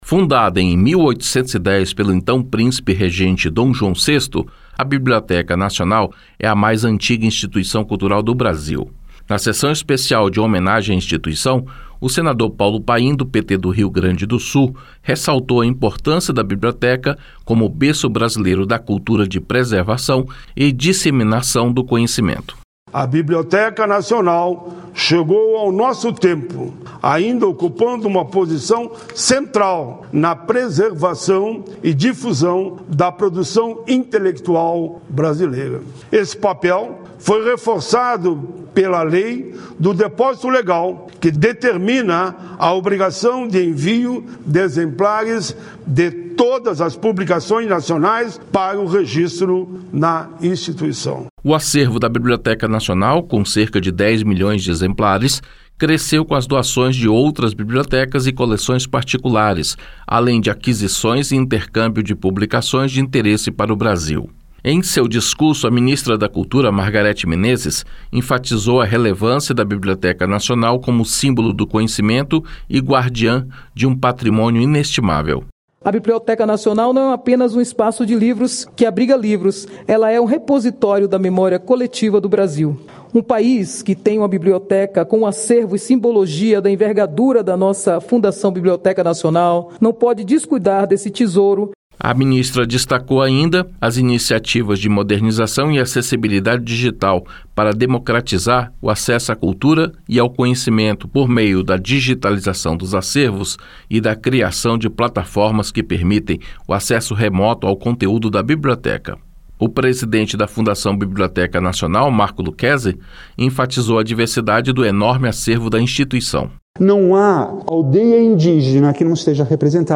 Sessão especial